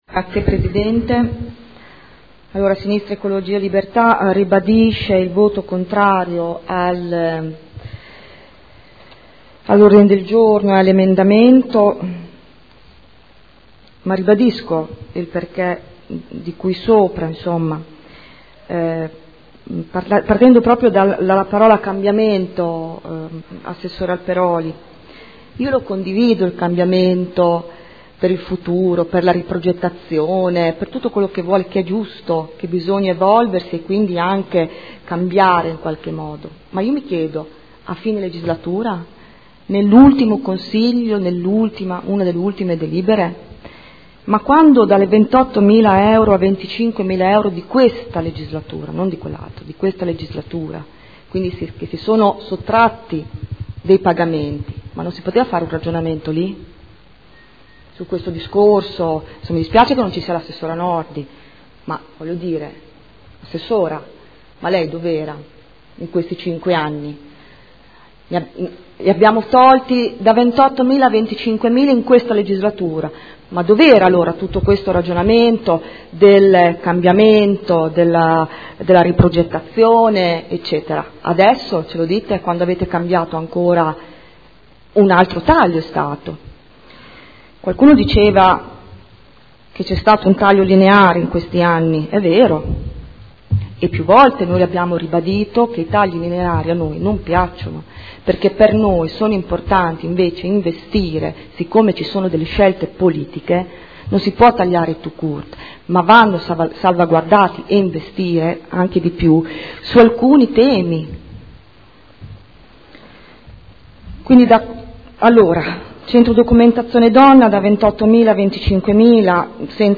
Dichiarazioni di voto